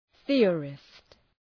Shkrimi fonetik {‘ɵıərıst}